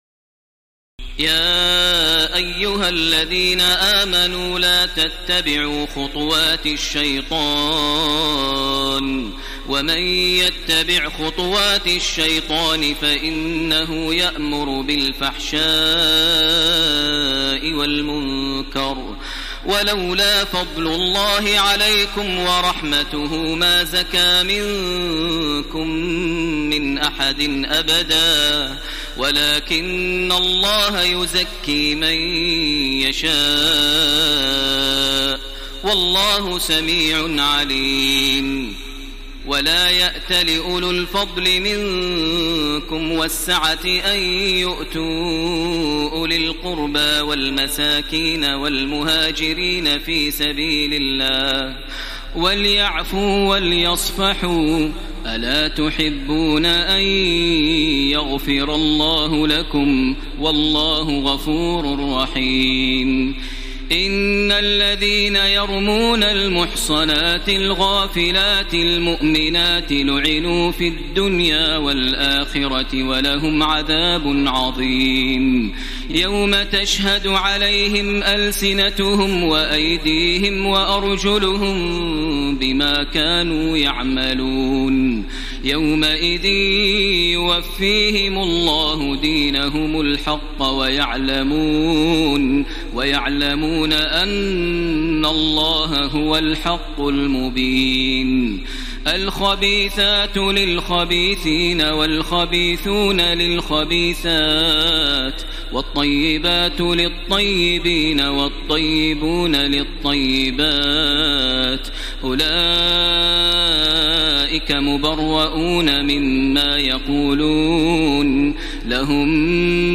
تراويح الليلة السابعة عشر رمضان 1434هـ من سورتي النور (21-64) و الفرقان (1-20) Taraweeh 17 st night Ramadan 1434H from Surah An-Noor and Al-Furqaan > تراويح الحرم المكي عام 1434 🕋 > التراويح - تلاوات الحرمين